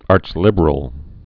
(ärchlĭbər-əl, -lĭbrəl)